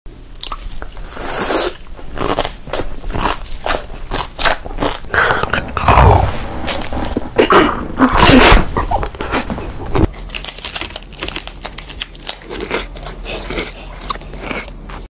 random Colors food mukbang Korean sound effects free download
ASMR Testing mukbang Eating Sounds